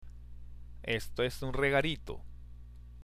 （エスト　エス　ウン　レガリート）